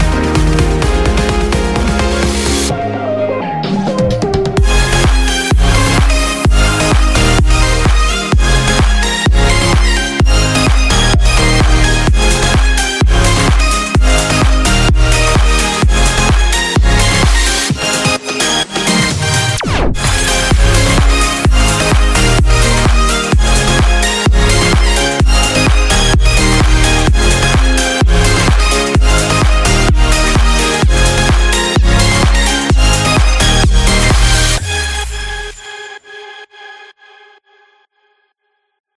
クリアで太くて音抜けが良い文句なしのサウンドです。
私自身Avengerをたくさん使ったEDMを作って見ました。
ほぼプリセットから動かしてない上にシンセもそんな重ねてないのですが、それだけで結構太くて存在感あるEDMサウンドが作れます。